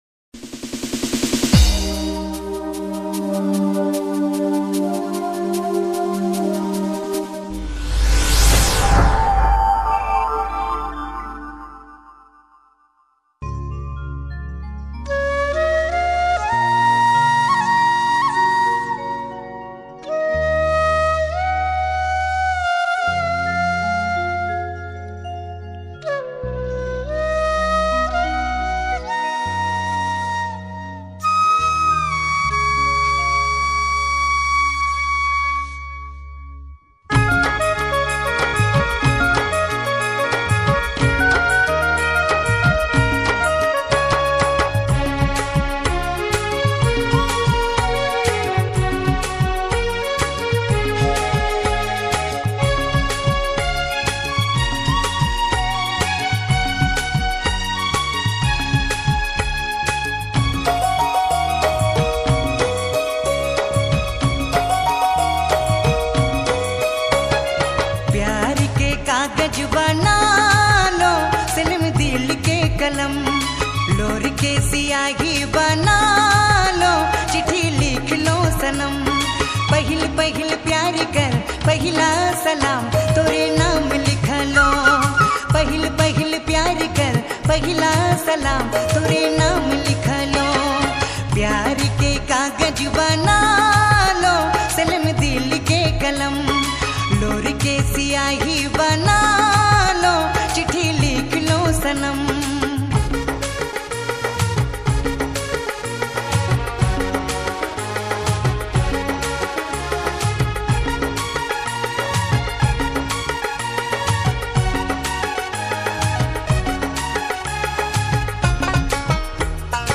Nagpuri music